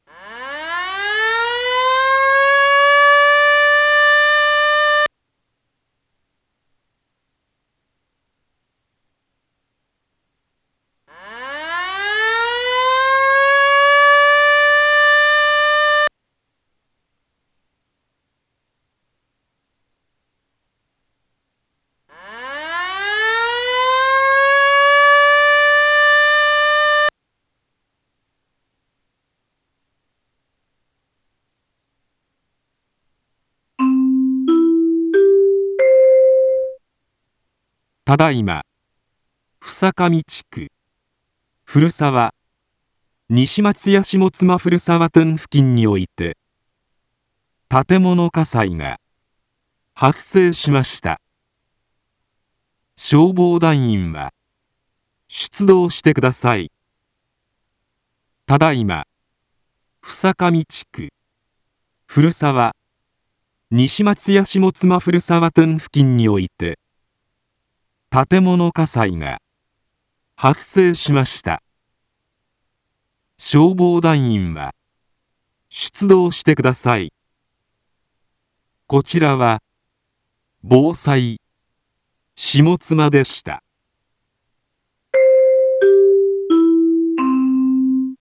一般放送情報
Back Home 一般放送情報 音声放送 再生 一般放送情報 登録日時：2025-07-05 18:18:47 タイトル：火災速報 インフォメーション：ただいま、総上地区、古沢、西松屋下妻古沢店付近において、 建物火災が、発生しました。